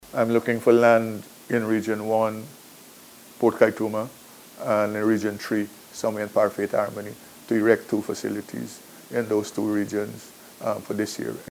In an exclusive interview with NCN, Minister Hamilton disclosed that ongoing construction projects in Region 6, including New Amsterdam and Corentyne, and Unity Mahaica will be complemented by the establishment of new facilities in Region 1, Port Kaituma, and Region 3, Parfait Harmony.